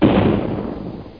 explo01.mp3